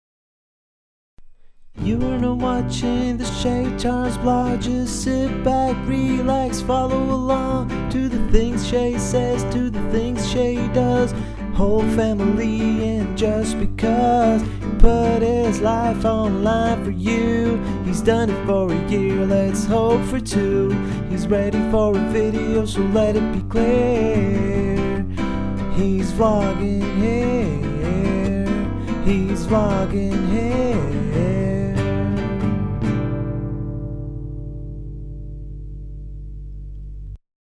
Hi guys, Recently got my M-Audio Firewire Solo and a Studio-Projects B1 mic out of the closet, and today was my first attempt at recording a small song. I recorded the acoustic guitar using the B1 and the DI, and then proceeded to pan them 50-50. I recorded bass through the DI, and vox using the same B1.
I feel like everything is "crowded" and on top of each other, and the guitar could be less muddy.